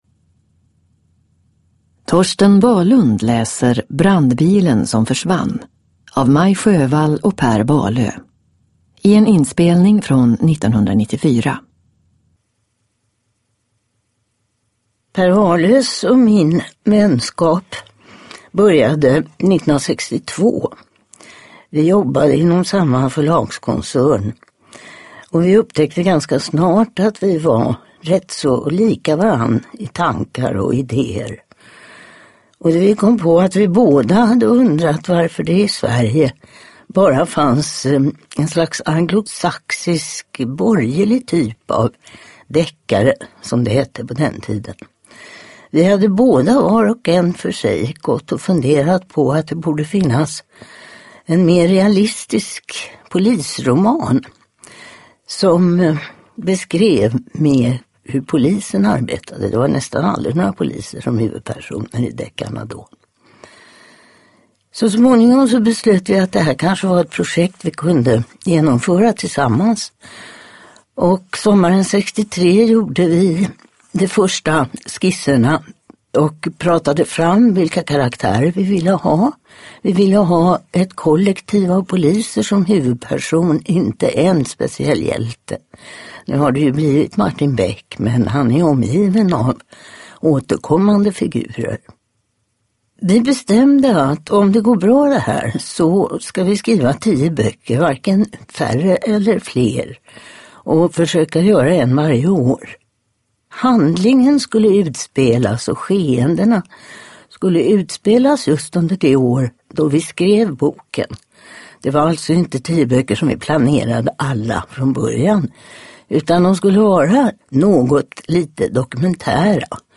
Brandbilen som försvann – Ljudbok – Laddas ner
Uppläsare: Torsten Wahlund